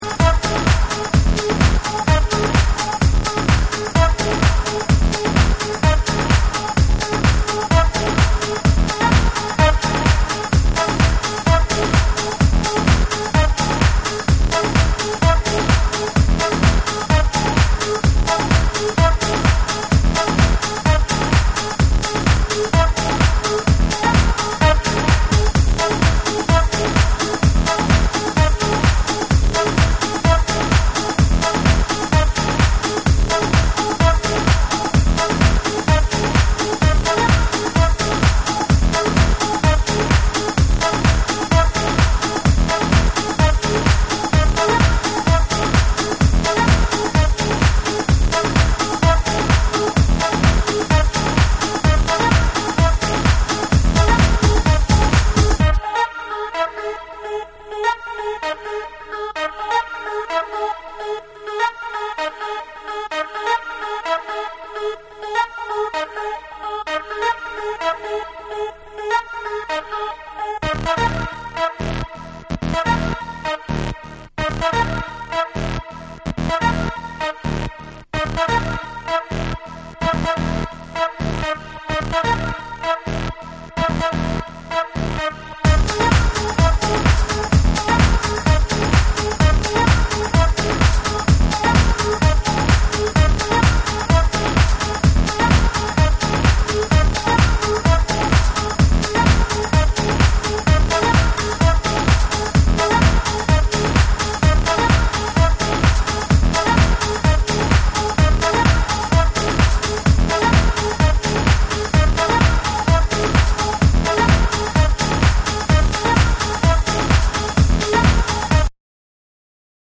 UKのテック・ハウサー
何処かオリエンタルな雰囲気も伺えるシンセ・フレーズとアシッドが混ざり合いプログレッシヴに疾走するフロア・チューン